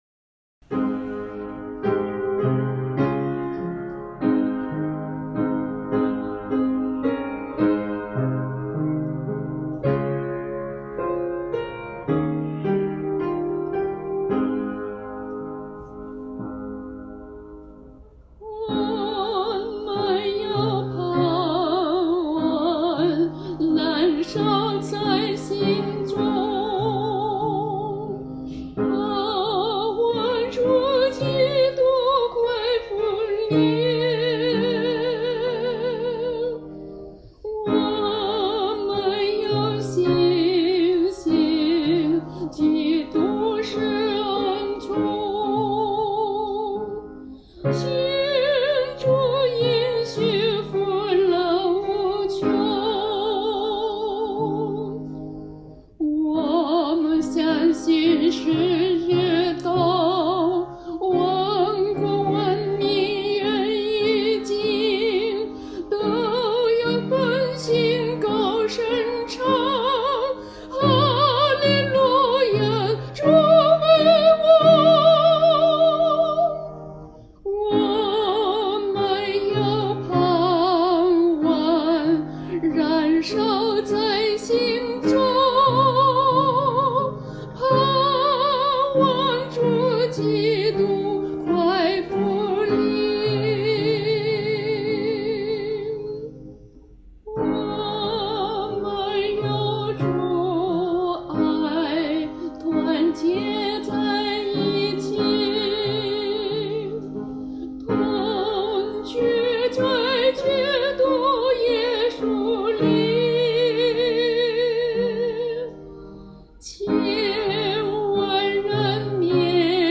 伴奏
示唱